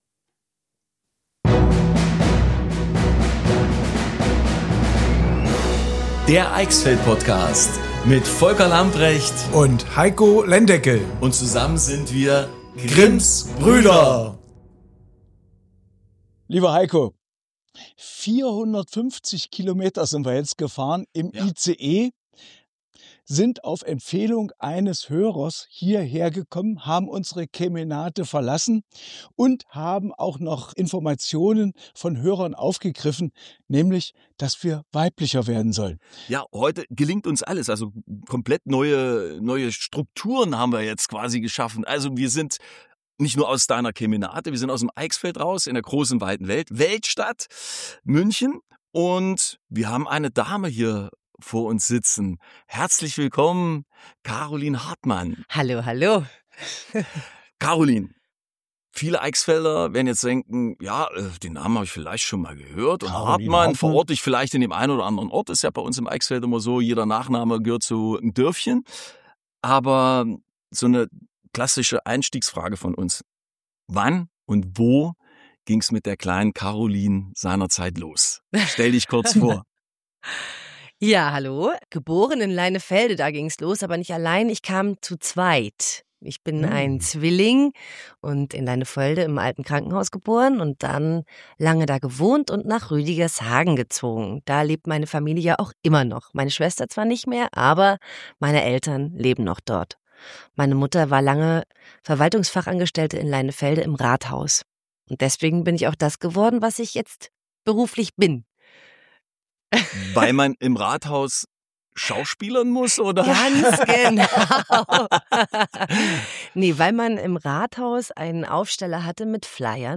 Eine Folge voller Lachen, ehrlicher Einblicke und Geschichten, die man so schnell nicht vergisst. Direkt aus München – aber mit ganz viel Eichsfeld im Herzen.